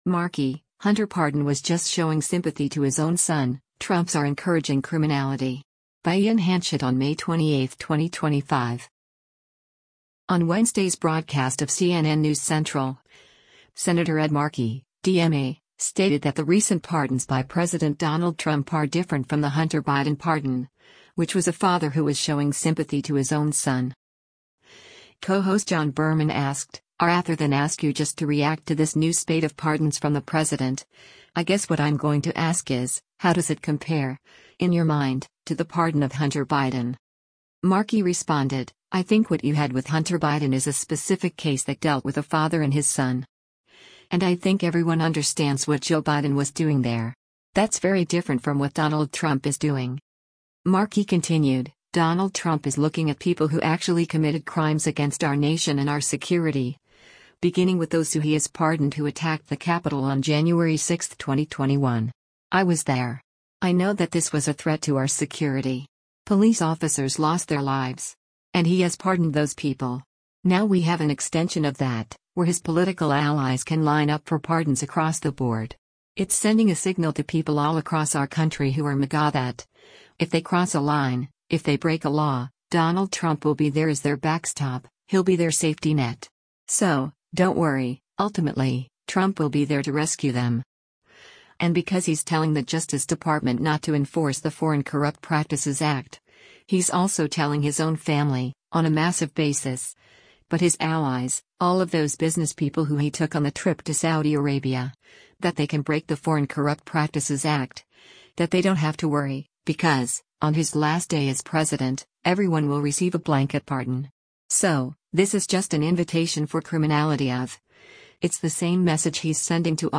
On Wednesday’s broadcast of “CNN News Central,” Sen. Ed Markey (D-MA) stated that the recent pardons by President Donald Trump are different from the Hunter Biden pardon, which was “a father who was showing sympathy to his own son.”
Co-host John Berman asked, “[R]ather than ask you just to react to this new spate of pardons from the President, I guess what I’m going to ask is, how does it compare, in your mind, to the pardon of Hunter Biden?”